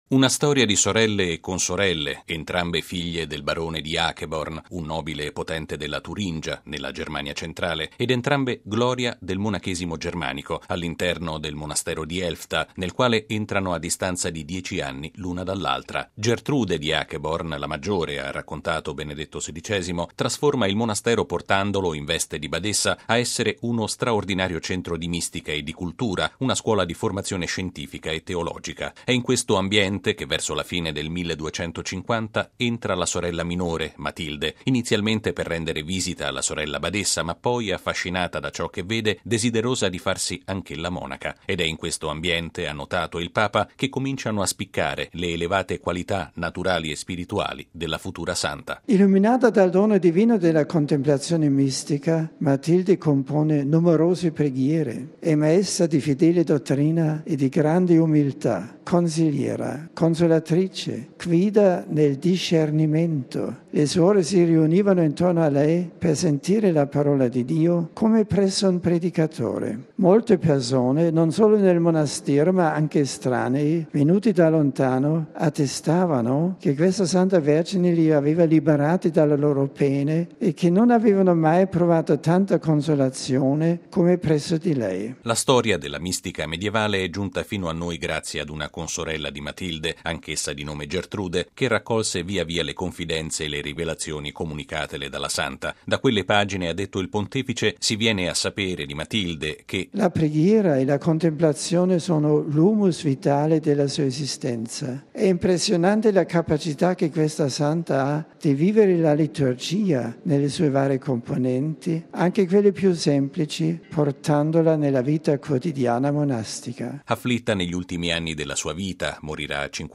E’ l’invito che Benedetto XVI ha rinnovato questa mattina all’udienza generale in Piazza San Pietro, durante la quale ha descritto alle circa 30 mila persone presenti la storia spirituale di Santa Matilde di Hackeborn, mistica tedesca del 13.mo secolo.